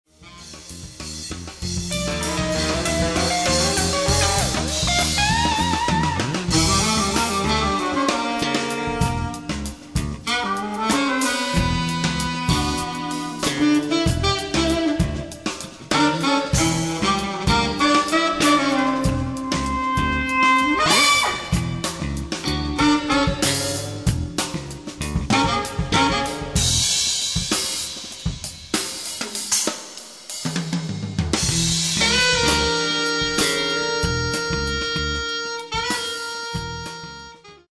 all saxophones
keyboards, programming, trumpet
bass, rhythm guitar
drums
flute
vocals
percussion